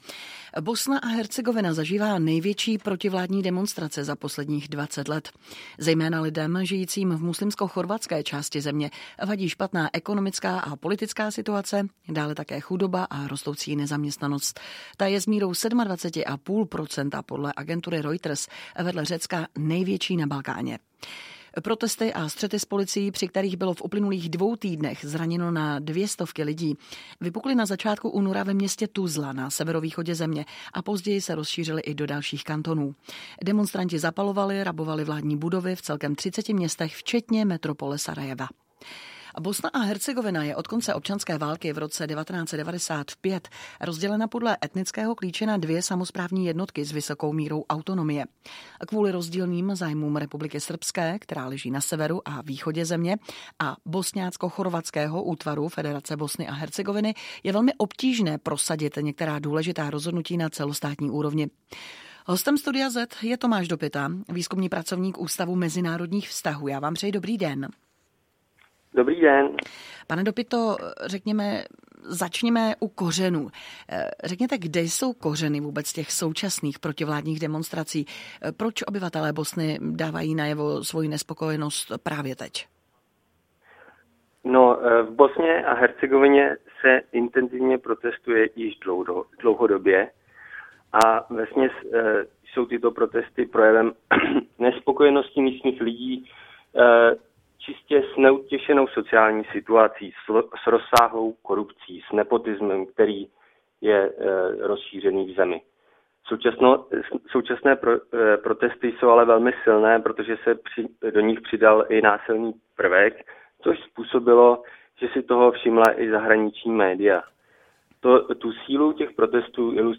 Záznam rozhovoru